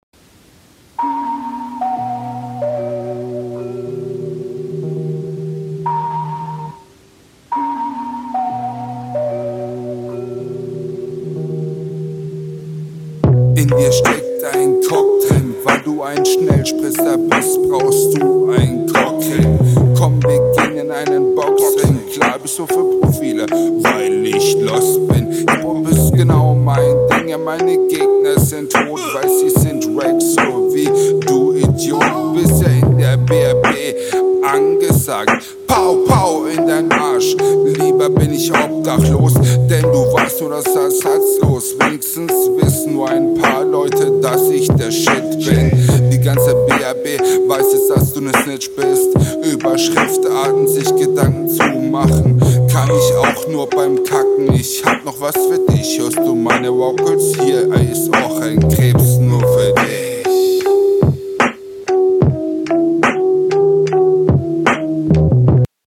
Flow ist sehr inkonsistent, gibt ausbaupotenzial.
Raptechnisch zwar coole raue Stimme, aber die holpert viel zu sehr rum ohne Rythmik aufkommen …